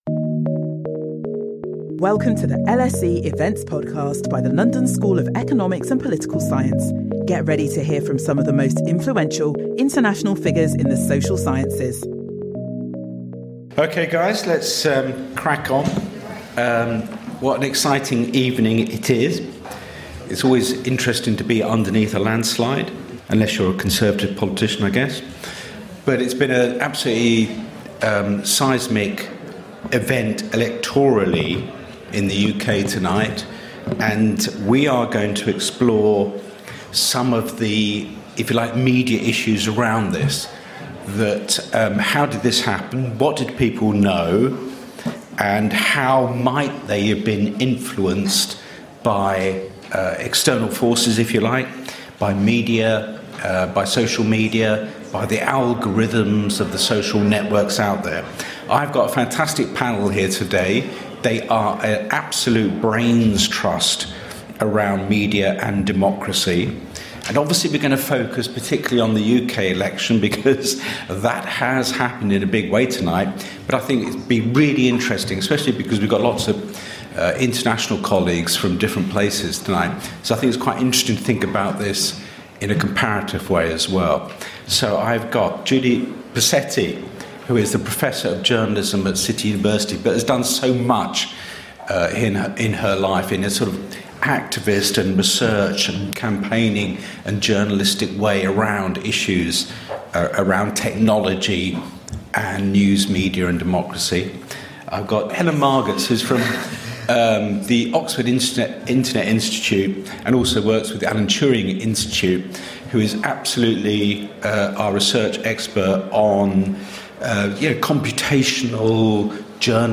Our panel discuss how AI and Fake News has shaped this election from digital to traditional media.